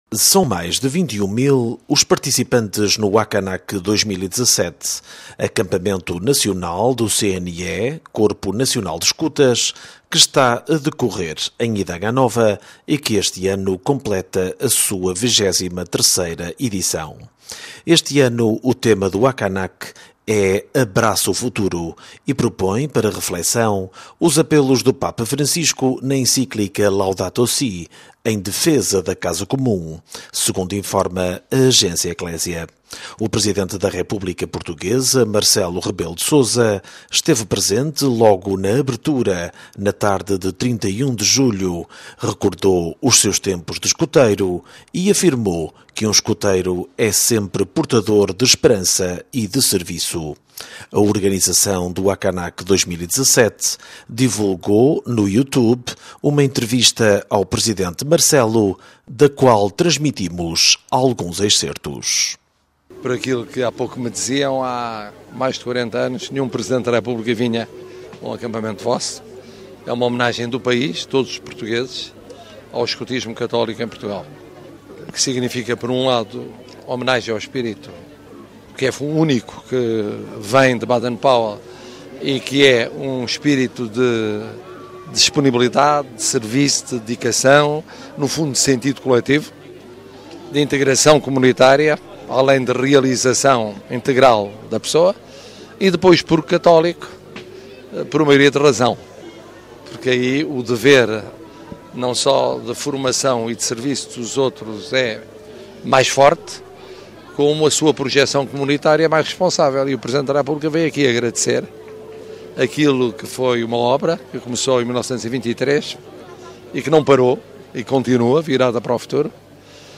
A organização do Acanac 2017 divulgou no Youtube uma entrevista ao Presidente Marcelo da qual publicamos alguns excertos:
Era o Presidente da República Portuguesa Marcelo Rebelo de Sousa durante a sua visita ao Acanac 2017.